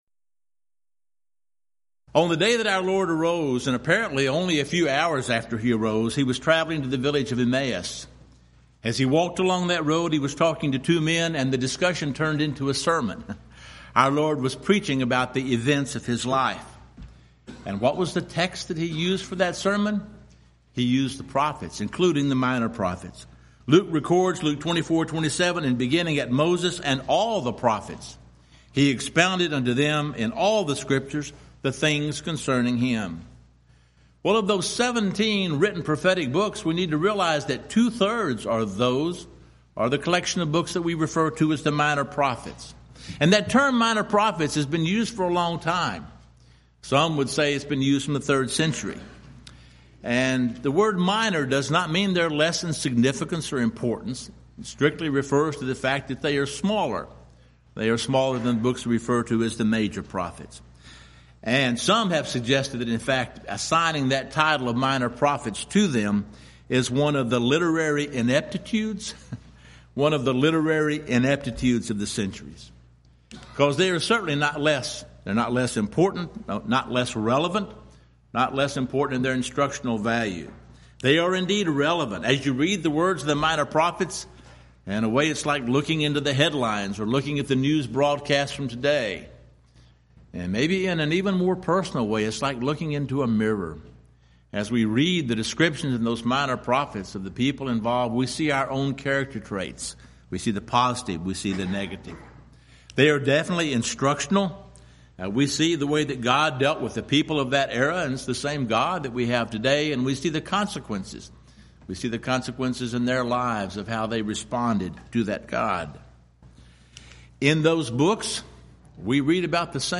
Event: 12th Annual Schertz Lectures Theme/Title: Studies in the Minor Prophets